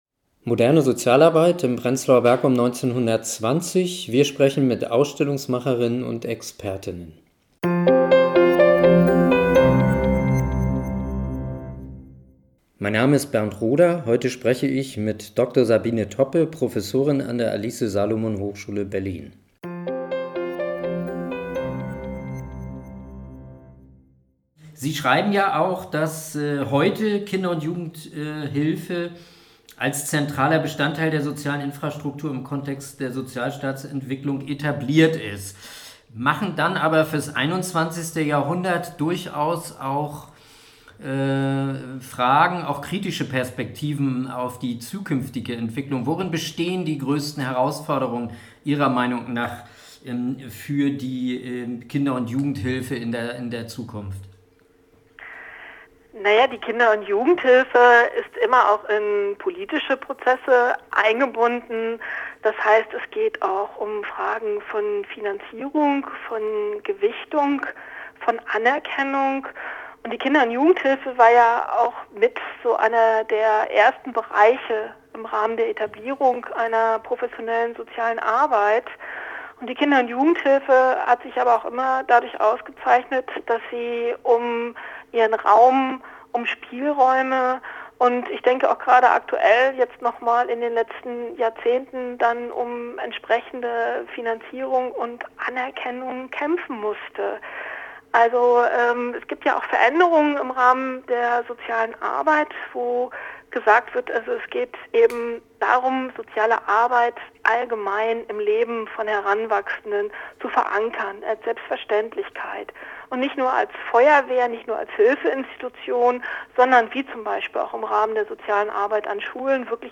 Interviews zum Thema: Aufbruch und Reformen – Pionierinnen und Pioniere der modernen Sozialarbeit in Prenzlauer Berg während der Weimarer Republik | Teil 2
Moderne Sozialarbeit in Prenzlauer Berg um 1920 – wir sprechen mit Ausstellungsmacher_innen und Expertinnen und Experten!